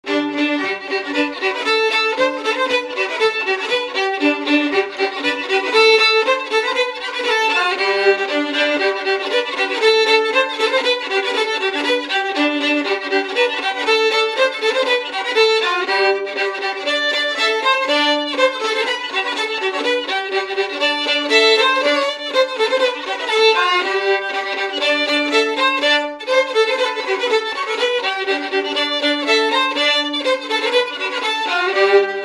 Résumé instrumental
danse : branle : avant-deux
Pièce musicale inédite